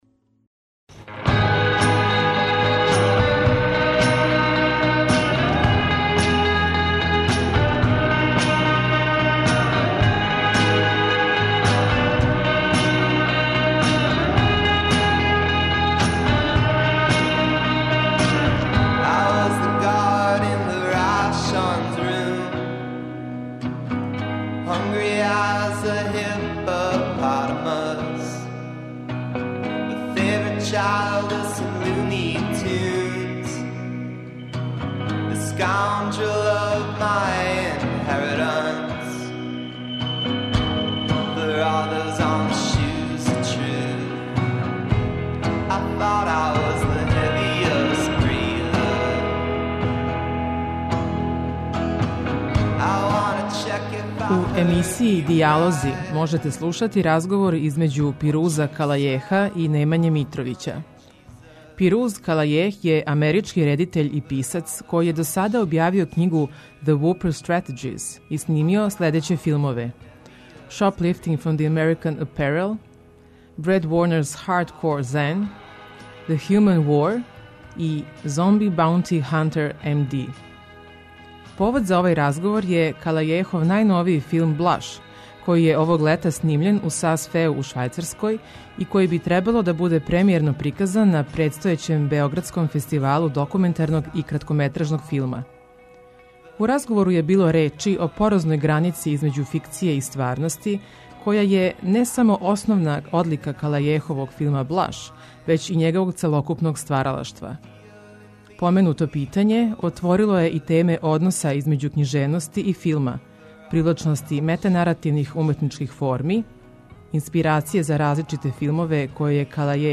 преузми : 15.40 MB Радијска предавања, Дијалози Autor: Трећи програм Из Студија 6 директно преносимо јавна радијска предавања.